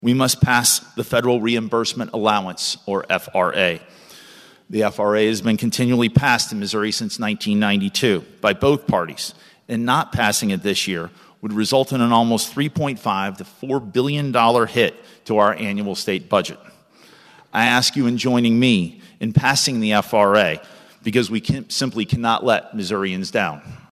House Speaker Dean Plocher (R-St. Louis) said failing to renew the FRA this year